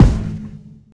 live_kick_4.wav